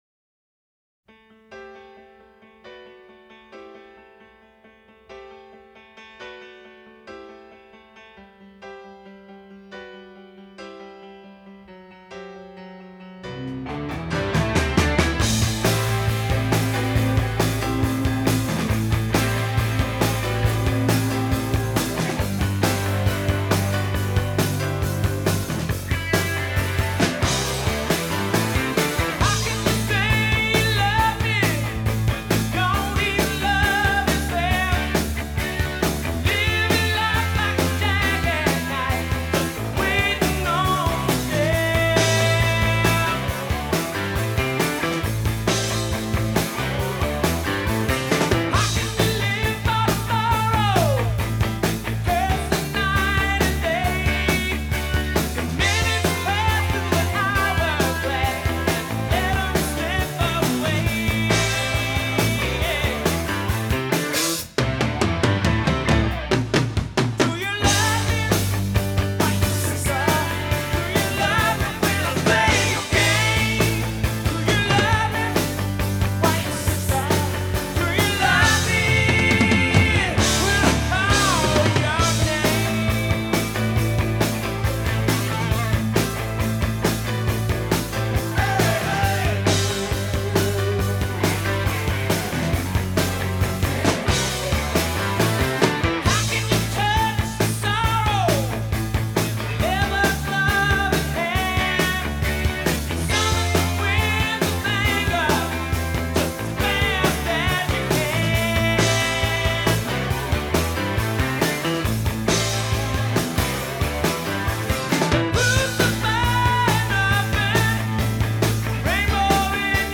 dè Coverband van Amsterdam en omstreken!